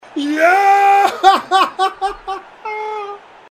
Play, download and share Jaaahahahaha original sound button!!!!
jaaaahahahaha.mp3